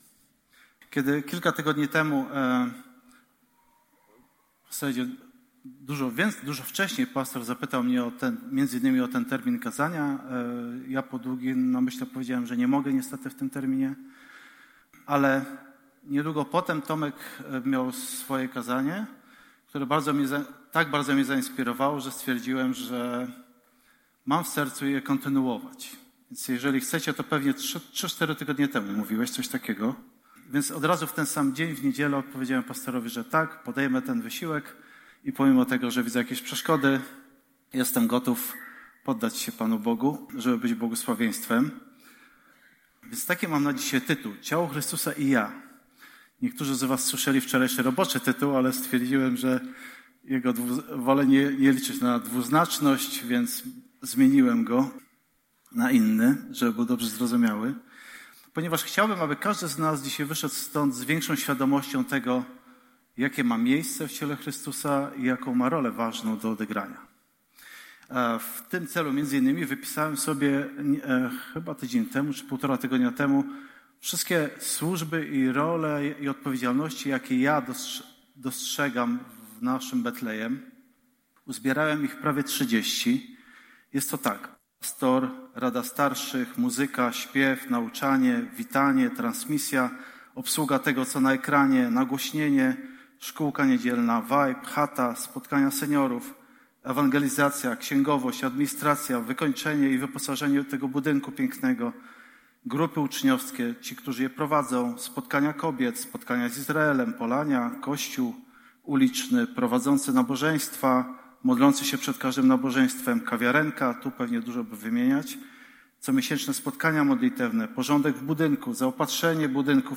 Transmisja nabożeństwa
Pytania do przemyślenia po kazaniu: 1) W jaki sposób Bóg okazał ci swoją troskę poprzez drugiego człowieka?